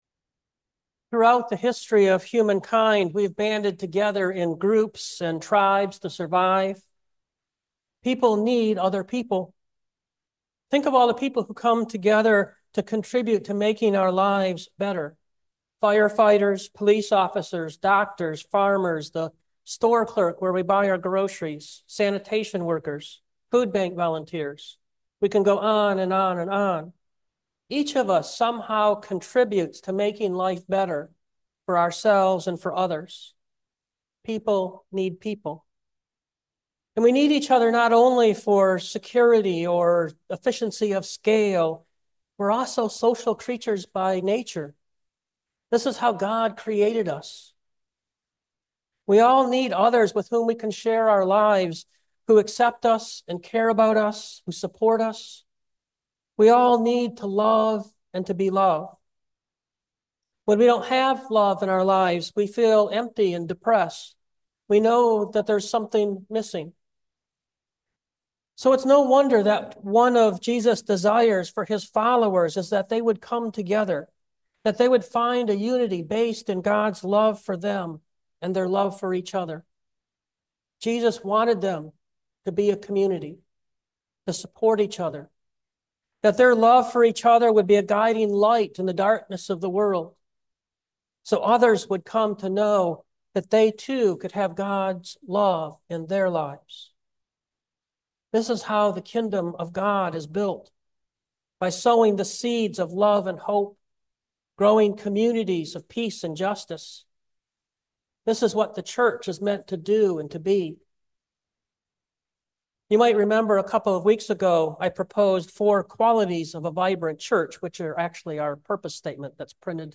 2024 Creating Community Preacher